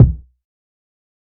TC3Kick21.wav